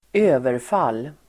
Uttal: [²'ö:verfal:]